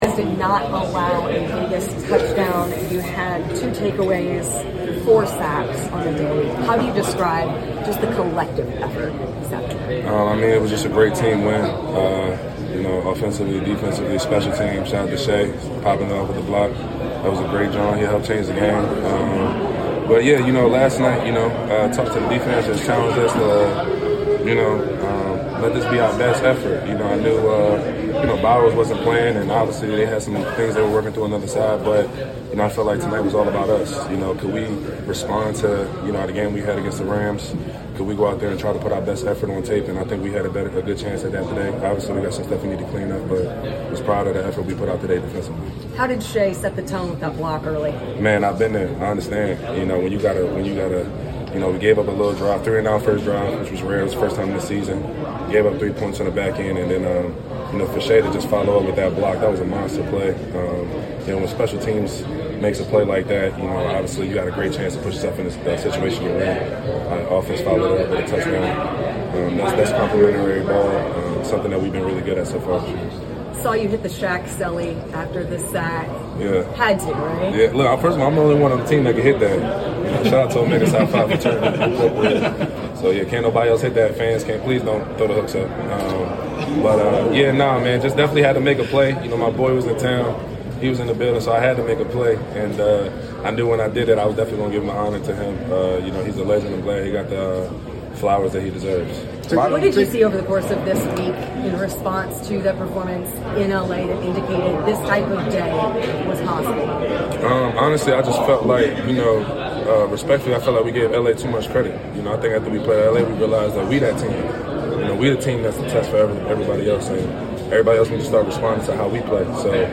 Indianapolis Colts Linebacker Zaire Franklin Postgame Interview after defeating the Las Vegas Raiders at Lucas Oil Stadium.